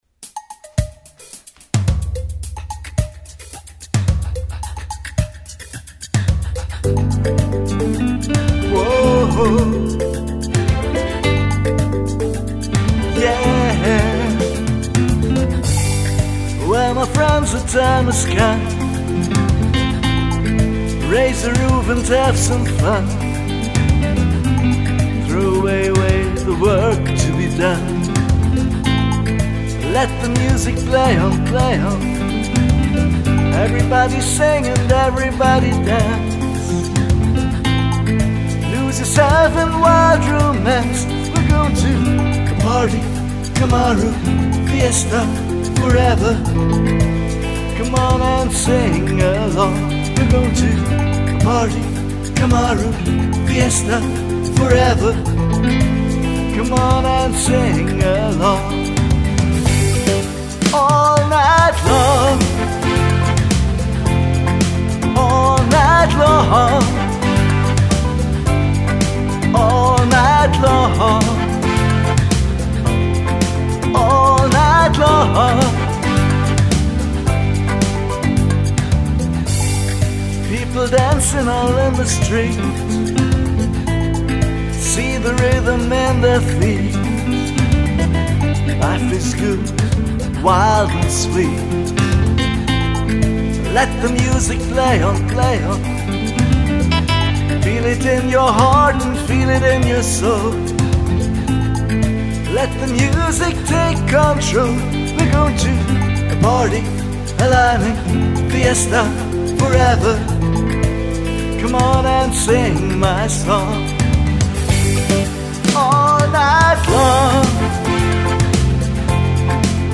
• Alleinunterhalter